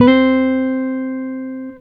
Guitar Slid Octave 13-C3.wav